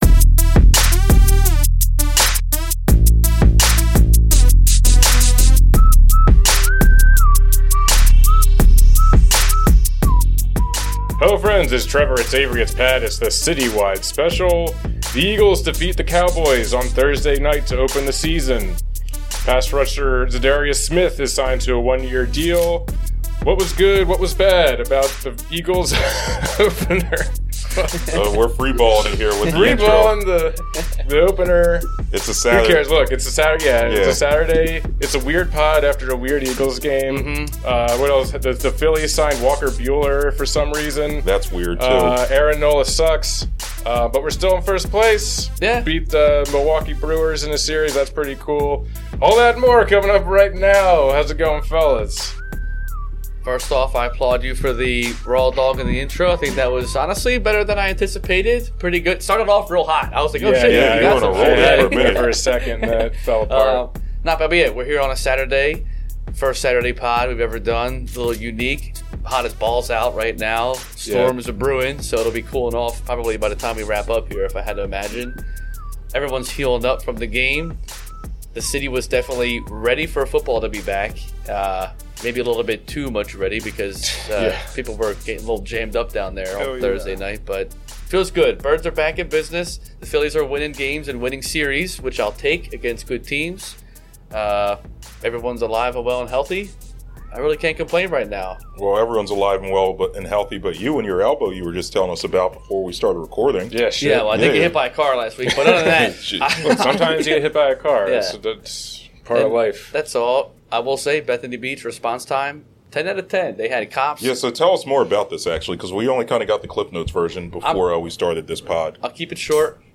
A tall can of Philly sports and a shot of banter.
The fellas are back to break down the Eagles week one victory over the Cowboys.